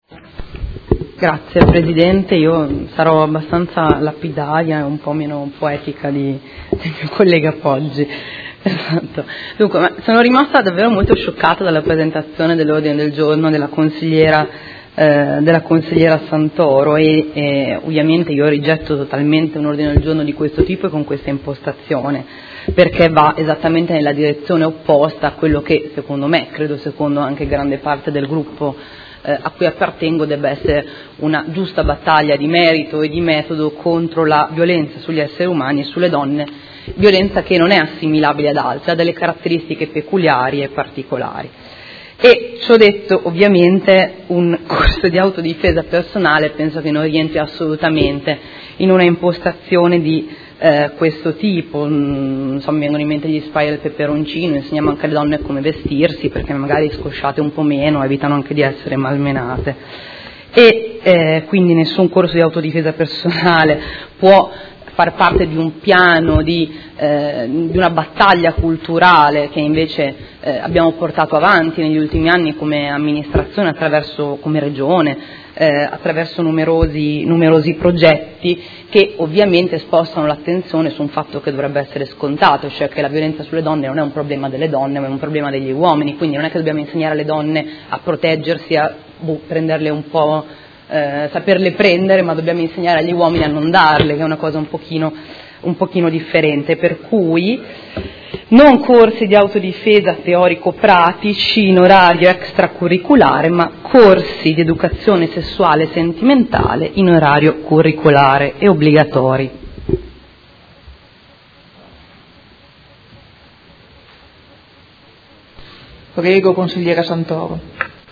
Seduta del 7 luglio.